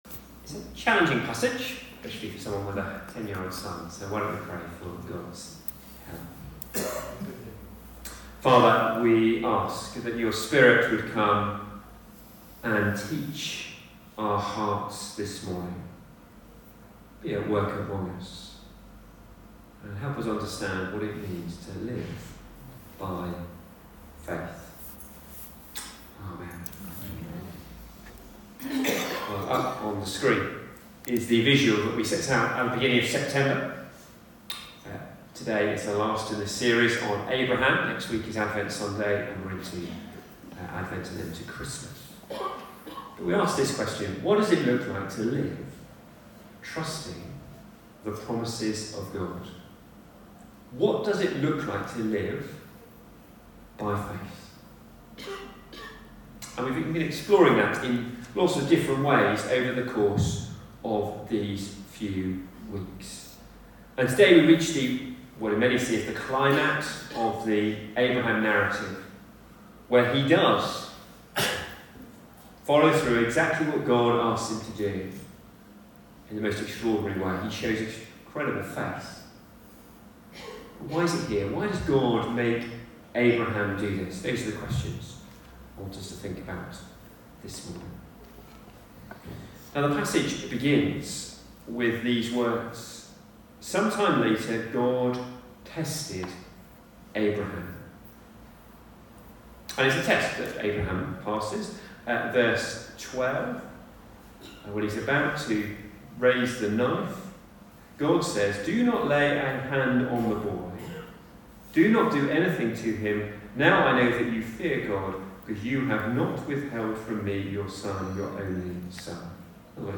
Recorded at St Ethelburga’s Church
Passage: Genesis 22:1-19 Service Type: 11am Communion Recorded at St Ethelburga’s Church « Sodom and Gomorrah Destroyed Change and Transformation by the Holy Spirit